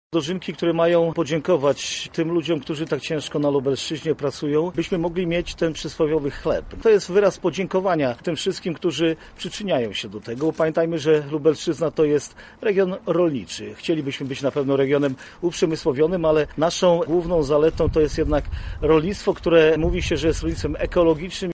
Więcej na ten temat mówi Marszałek Województwa Lubelskiego Jarosław Stawiarski: